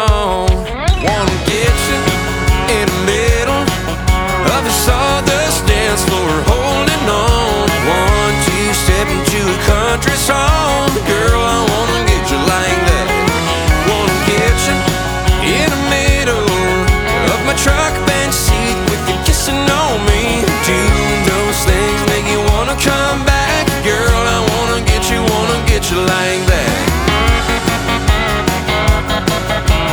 Honky Tonk File Size